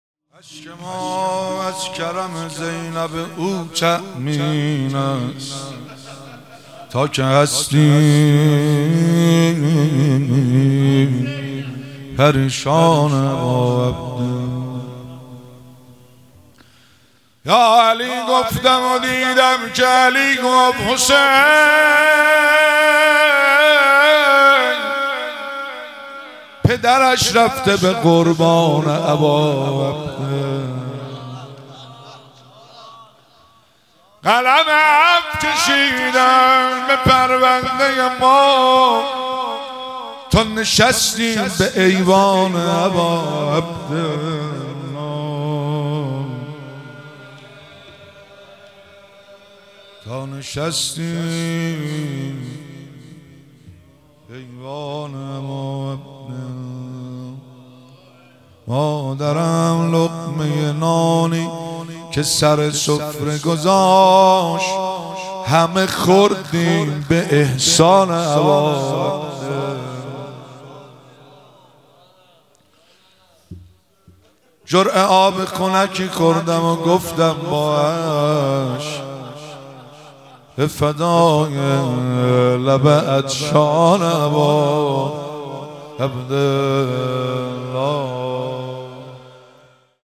مراسم مناجات خوانی شب دوم ماه رمضان 1444